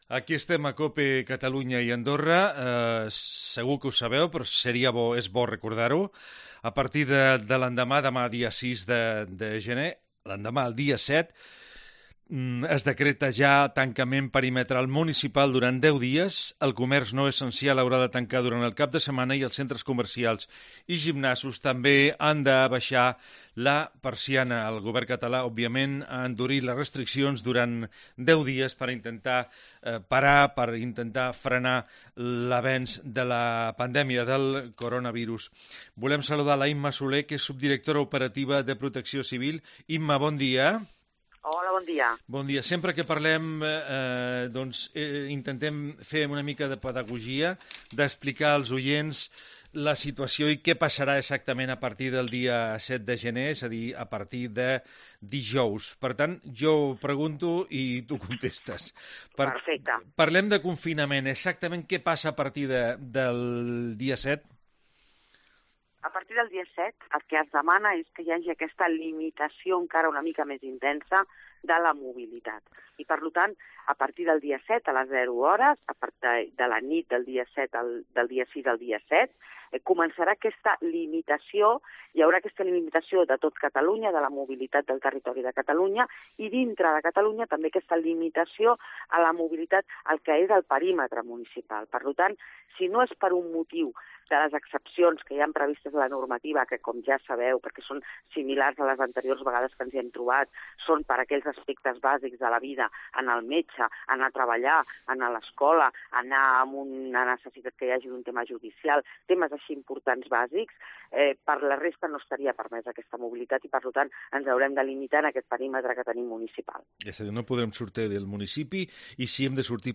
Si el teu horari d'escoltar ràdio és a partir de les 13 i fins a les 14 hores no et perdis 'Migdia Cope Catalunya i Andorra'.Un temps de ràdio en directe amb les millors entrevistes musicals dels artistes que triomfen o els escriptors que presenten les seves últimes novetats literàries.